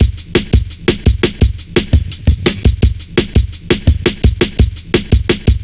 Index of /90_sSampleCDs/Zero-G - Total Drum Bass/Drumloops - 3/track 62 (170bpm)